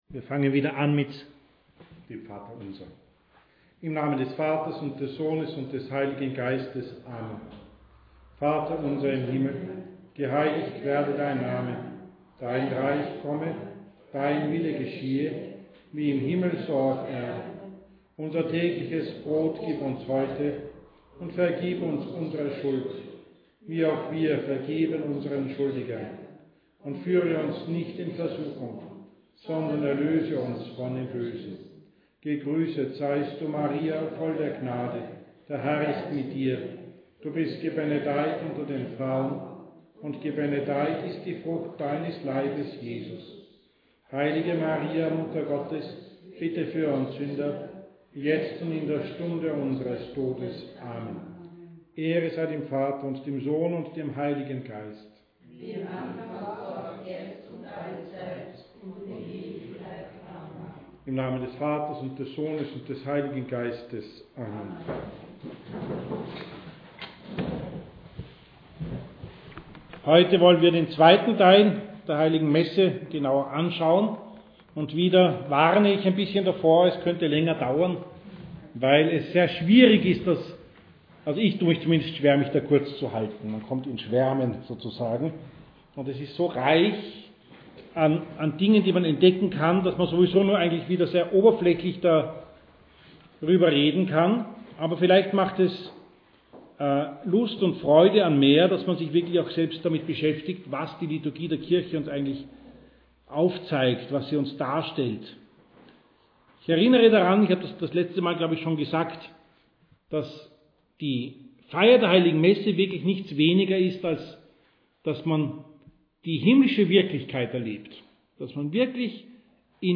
Katechese über die Heilige Messe zum Nachhören